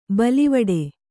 ♪ balivaḍe